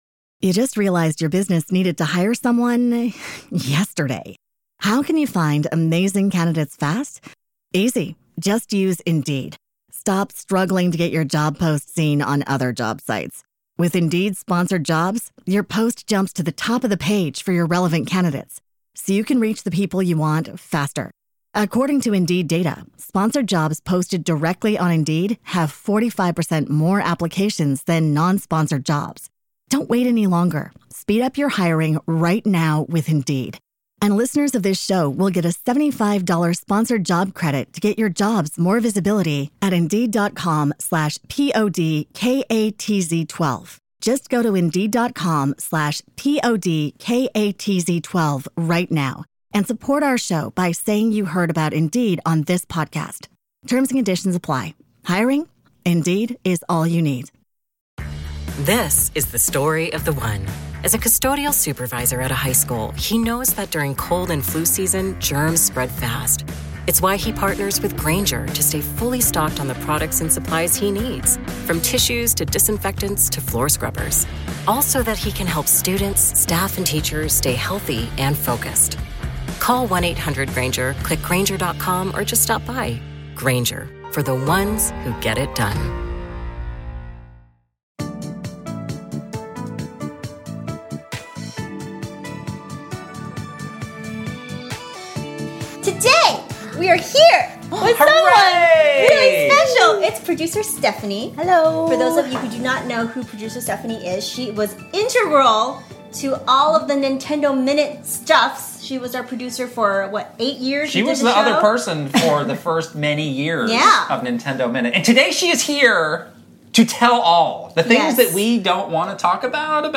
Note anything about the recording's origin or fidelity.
She's here in-studio to air all her grievances with us (just kidding) but she does have some really fun stories and even things WE don't know about.